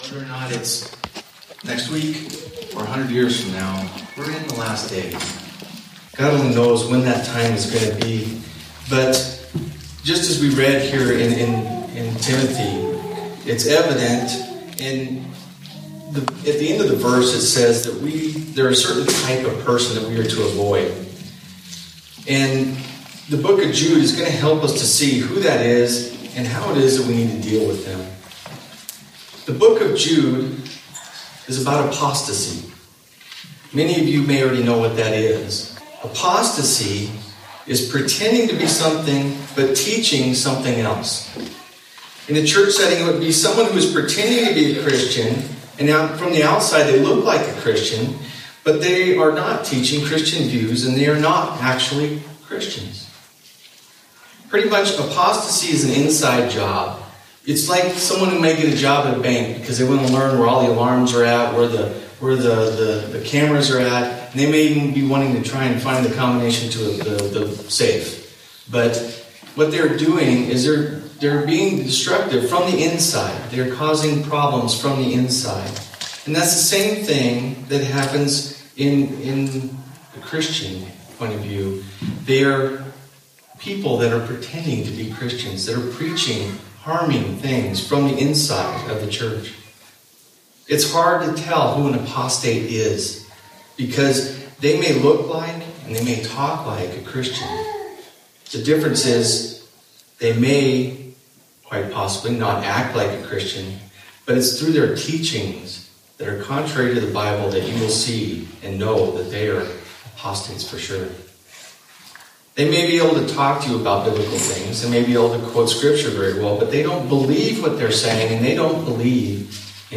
Due to the absence of the digital recorder, this sermon was recorded “open air” style with an iPod. I have attempted to edit out as much chamber echo as I can. I also attempted to edit out the joyful sounds of our toddlers, but after sixteen minutes in and 75 edits, I decided that it was making the recording too choppy.